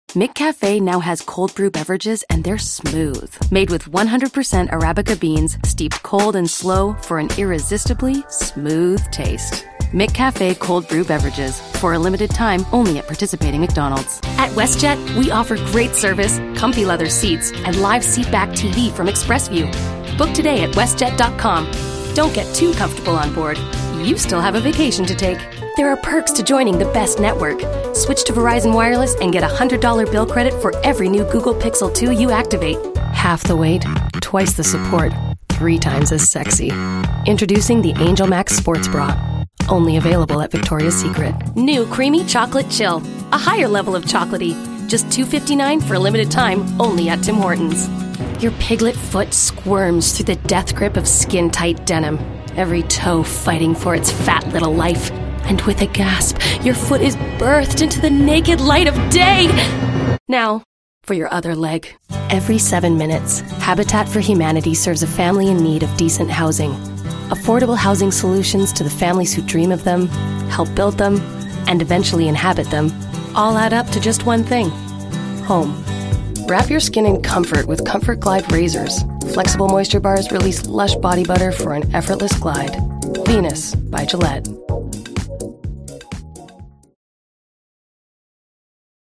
Inglés (Cánada)
Una voz auténtica, conversacional y tranquila, sin la típica voz de un locutor.
Juvenil
Enérgico